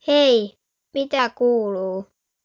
Spraaksynthese met de stem van een kind
Otto’s stem is gemodelleerd naar een 9-jarige jongen, maar met de veelzijdige stembedieningsinstellingen van DialoQ Speech kan deze worden aangepast aan oudere jongens en jongere meisjes.
Otto 9j (Fins):